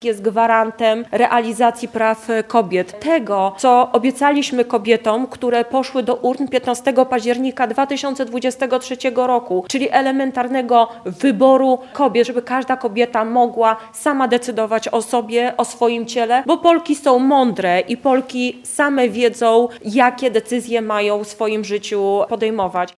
Kampanię wyborczą Rafała Trzaskowskiego podsumowała dziś w Zamościu posłanka Małgorzata Gromadzka.